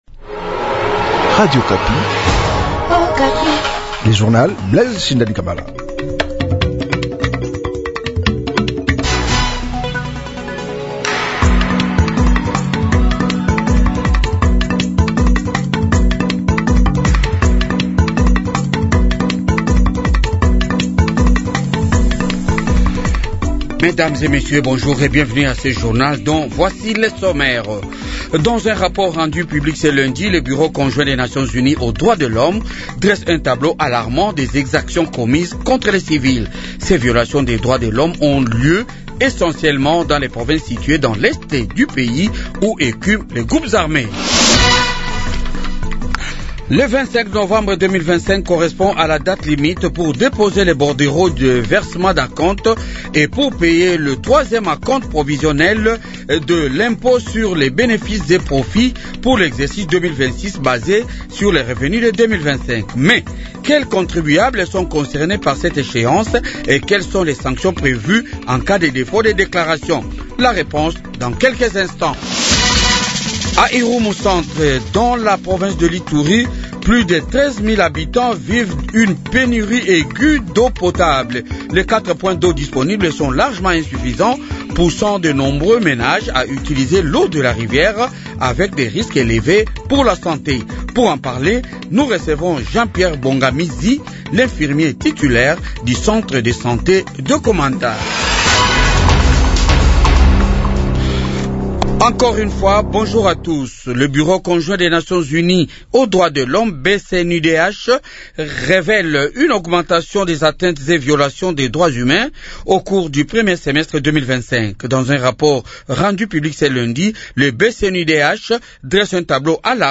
Journal de 8h